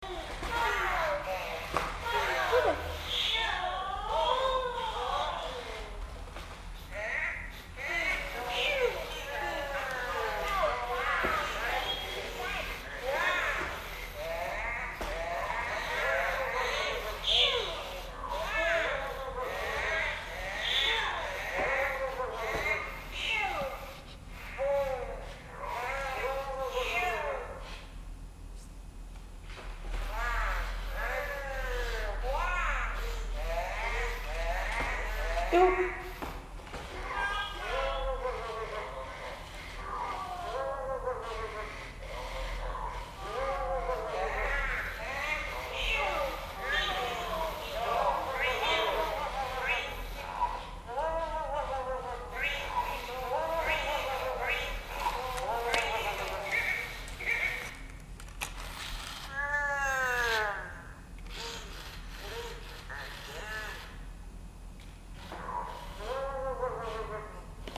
Gaggle of Furbies in a Stairwell
furbies.mp3